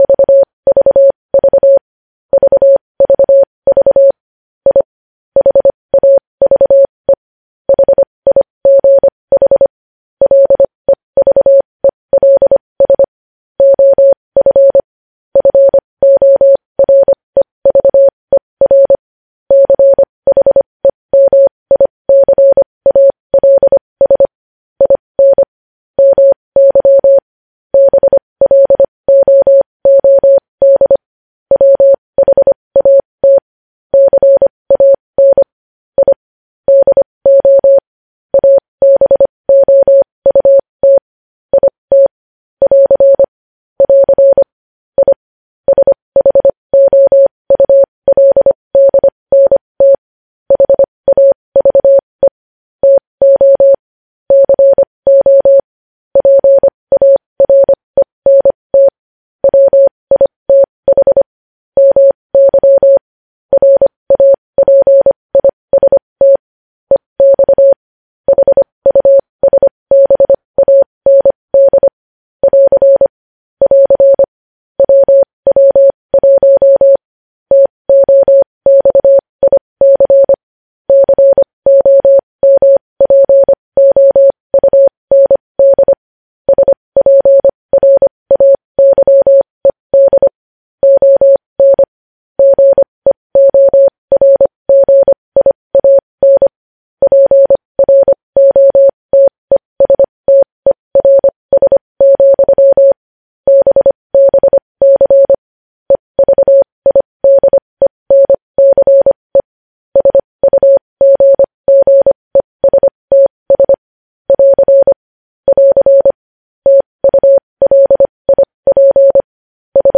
News Headlines in Morse Code at 20 WPM
News Headlines in Morse code, updated daily